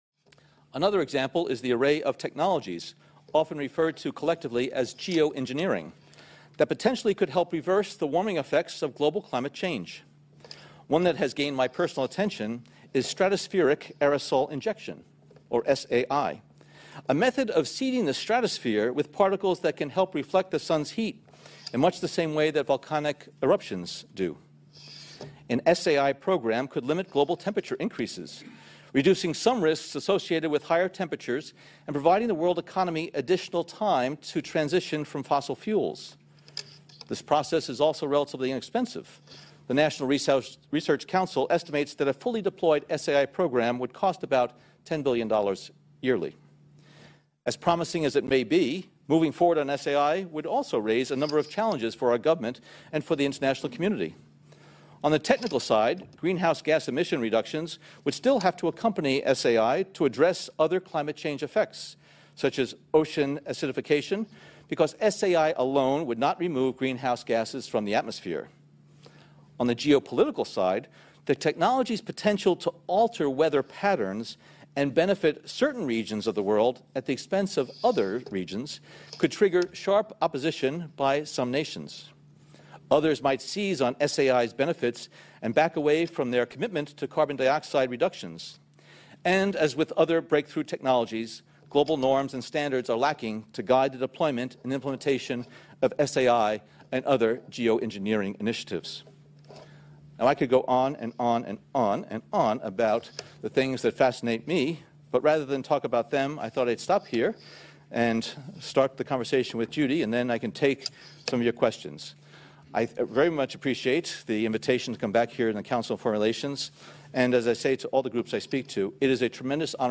CIA Director speaks about Geoengineering as a fact